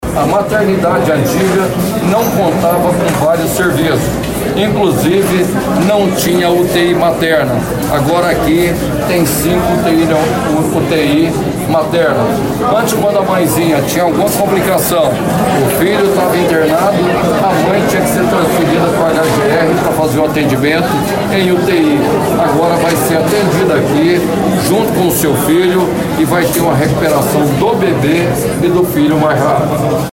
Ouça aqui a fala do governador anunciando 5 leitos de UTIs maternas: